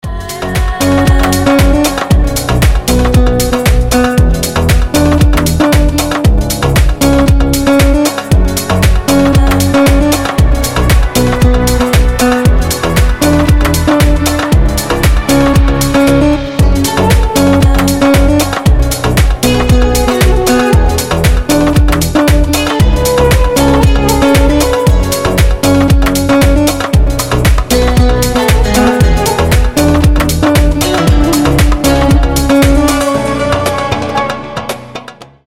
восточный дипчик